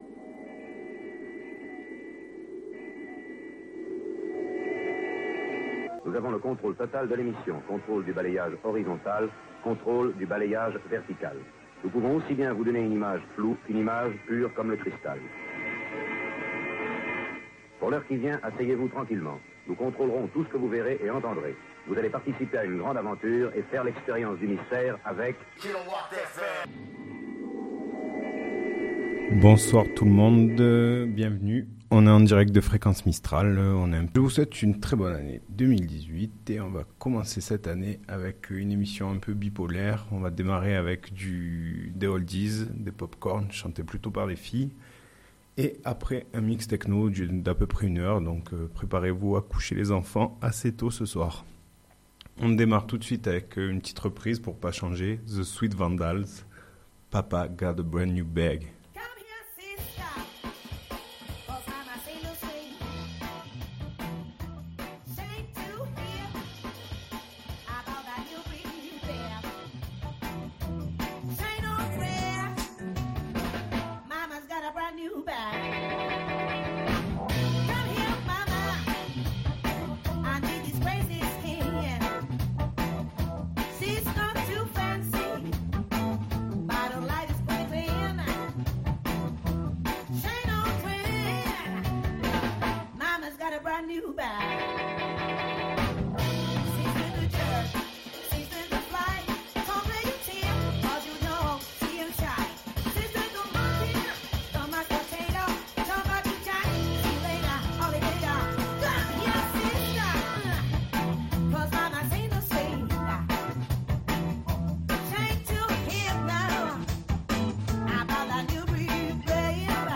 Il nous propose un rendez-vous hebdomadaire le mardi de 20h30 à 22h pour partager avec les auditeurs de Fréquence Mistral Digne des musiques variées allant du Rhythm and Blues, au Hip-Hop.